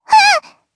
Lewsia_A-Vox_Damage_jp_03.wav